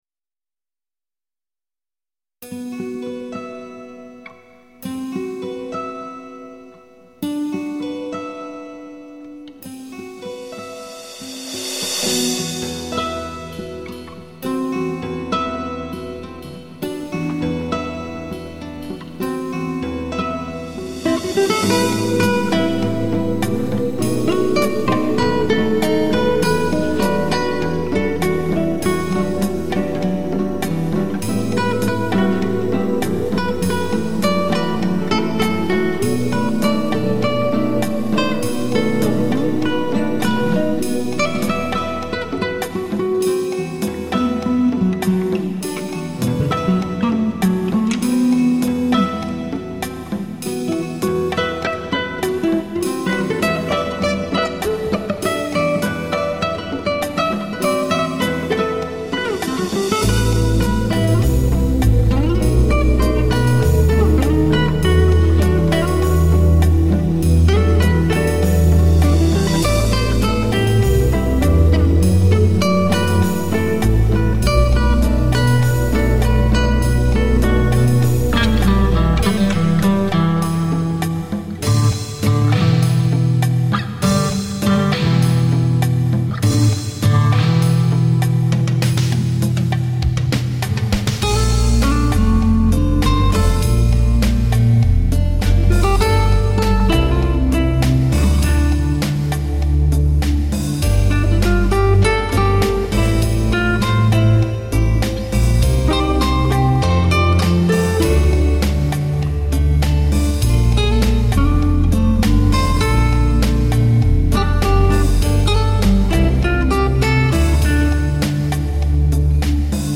十年ぶりに生ギター録音に挑戦。